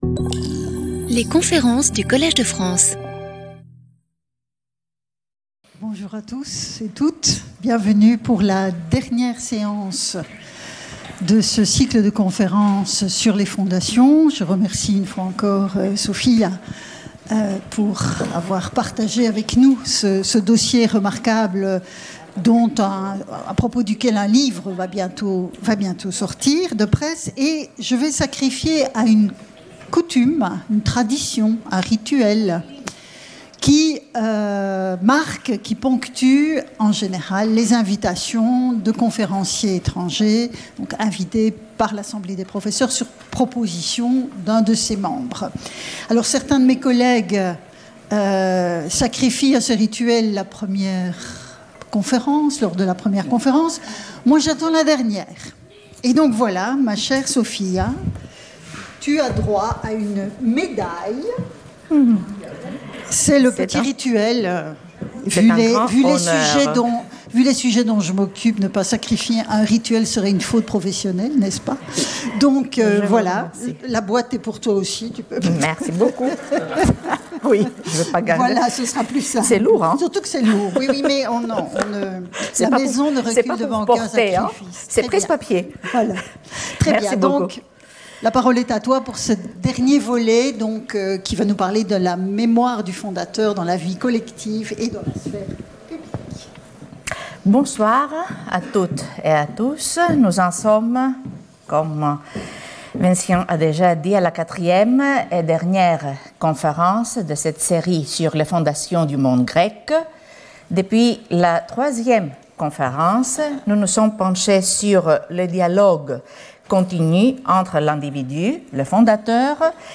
Guest lecturer